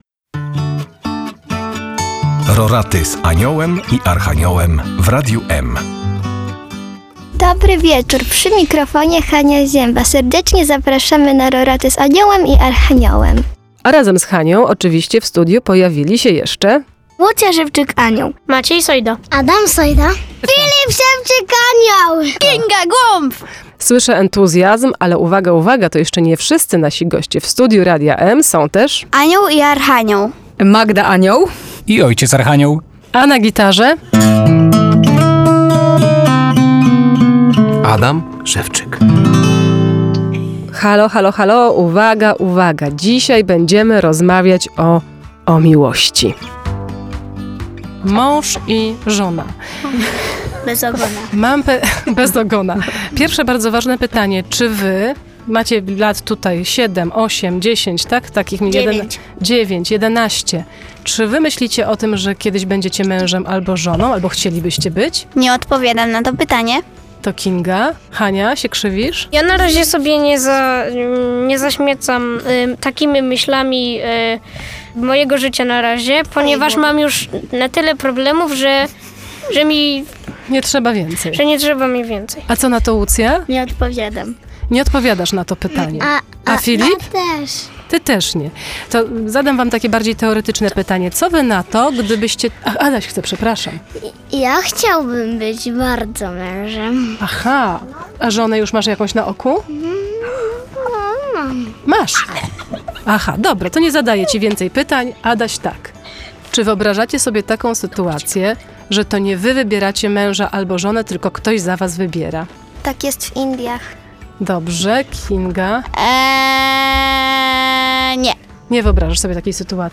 dzieci prowadzą tegoroczne Roraty na antenie Radia eM